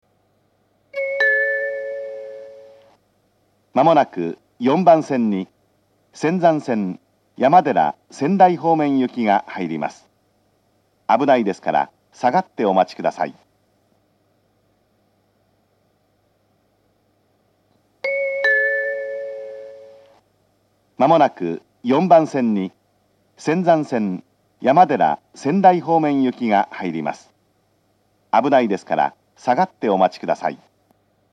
４番線上り接近放送